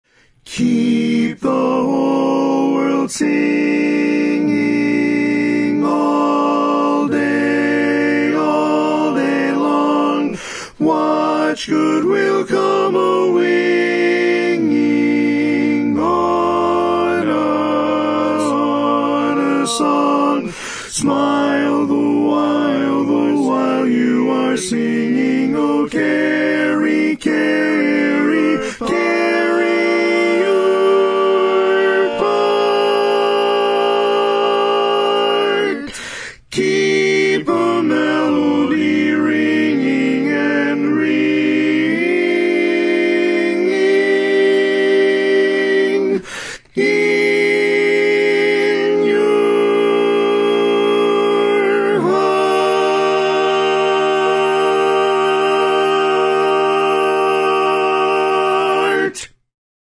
Tenor Part
Keep the Whole World Singing - Tenor.mp3